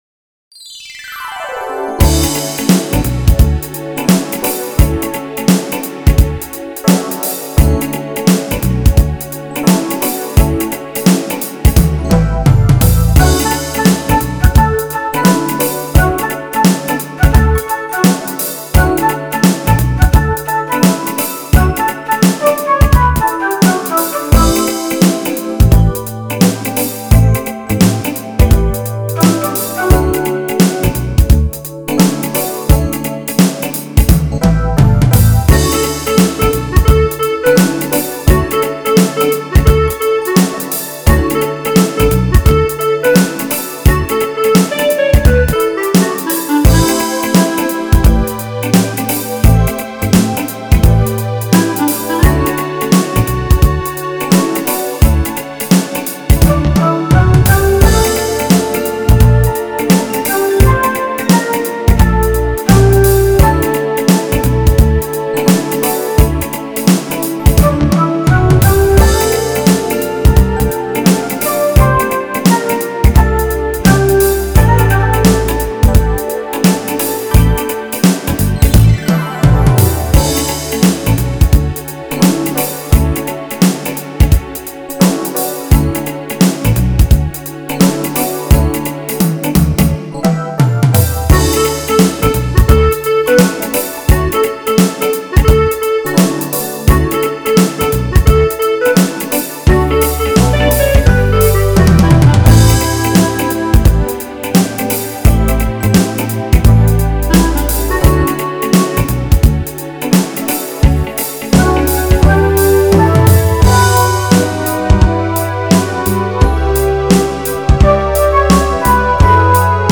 Never mind the words, it’s the beat that counts on this one.
Track 4 is the lead, so obviously that has to go.